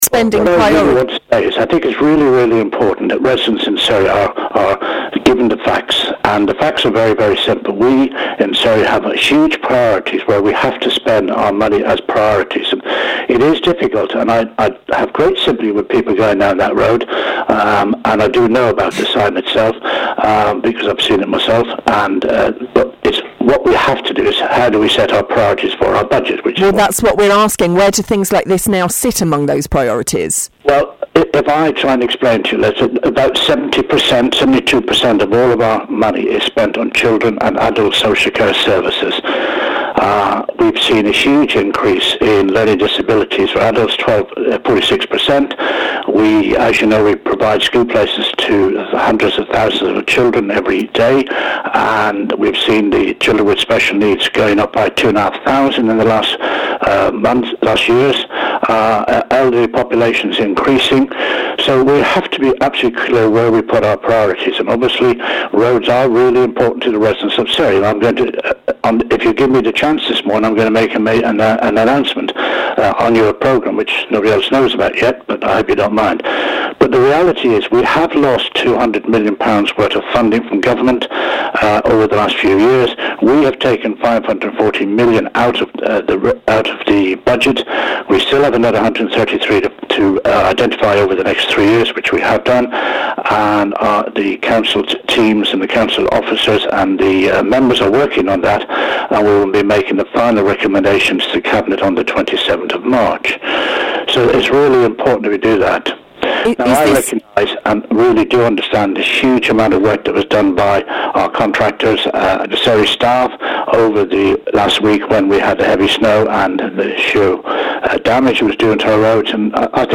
David Hodge speaks to BBC Surrey about highways
Audio courtesy of BBC Surrey.
david-hodge-interview-on-bbc-surrey-march-7.mp3